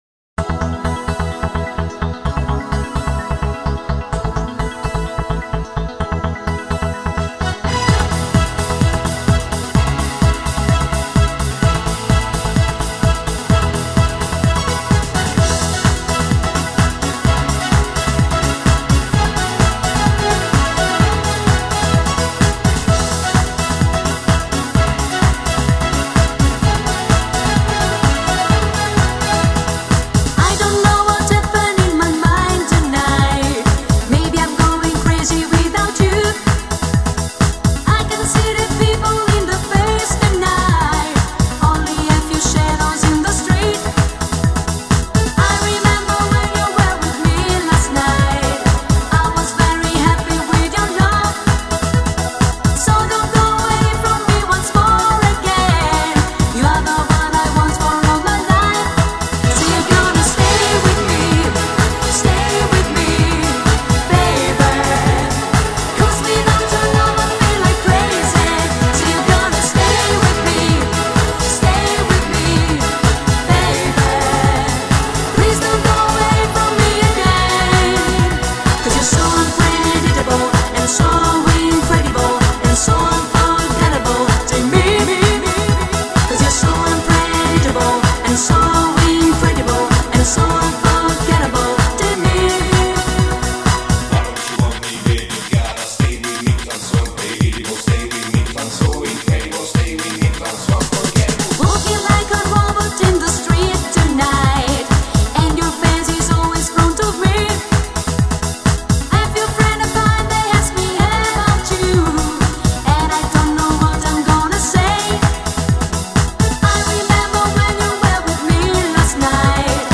Genere: Disco dance